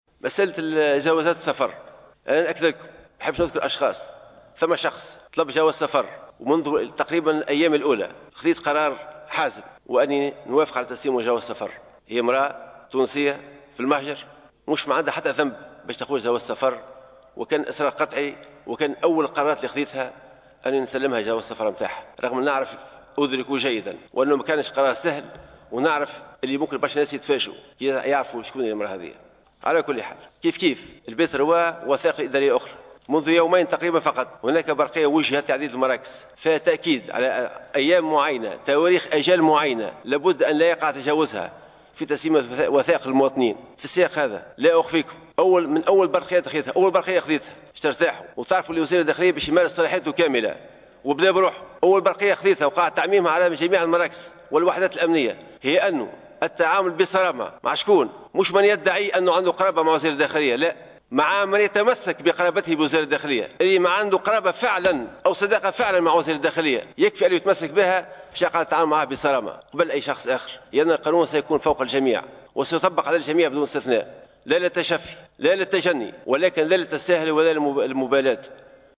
ورفض الوزير خلال جلسة عامة بالبرلمان للحوار مع أعضاء الحكومة، الكشف عن هوّية السيّدة مكتفيا بالقول،" كُنت أدرك جيّدا أنه لم يكن قرارا سهلا وستتفاجأون لو أطلعتكم عن هويتها".